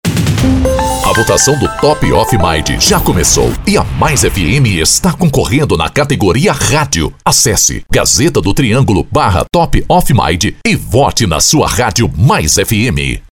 Pode usar sua criatividade e impostação também.
A pronuncia MIND está correta, mas faltou o som o "i" no final.